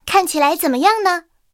I号查看战绩语音.OGG